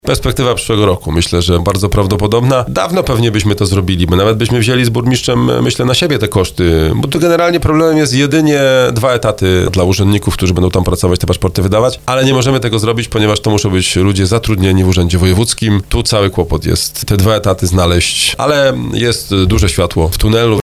O perspektywie utworzenia biura starosta mówił w rozmowie Słowo za Słowo.